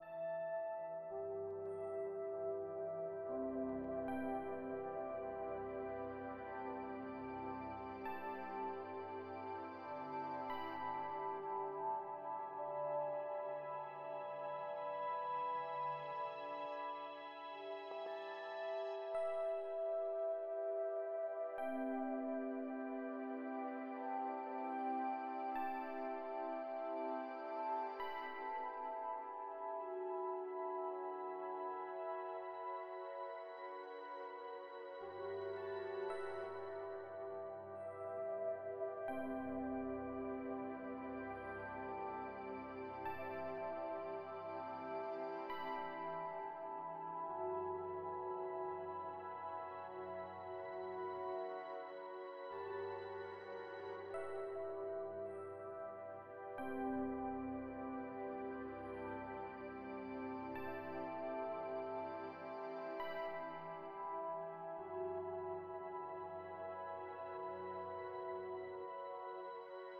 SOUND DESIGN